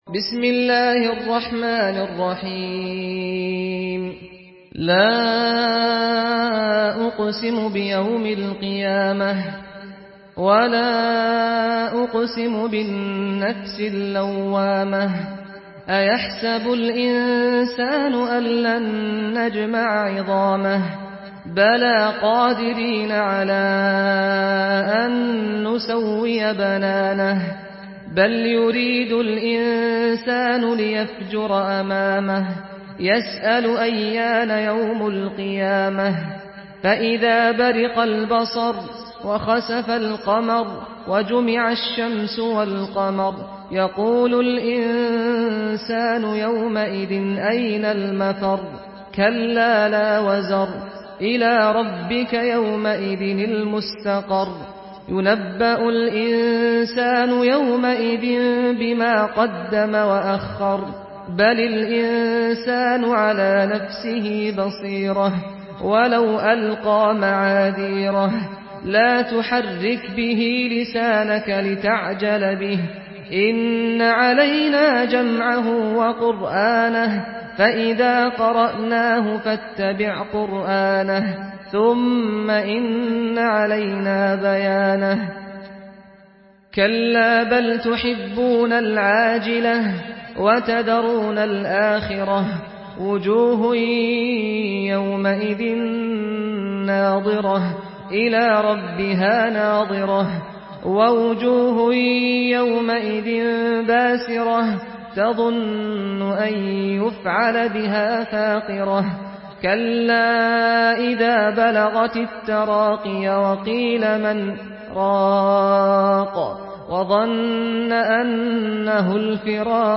سورة القيامة MP3 بصوت سعد الغامدي برواية حفص
مرتل حفص عن عاصم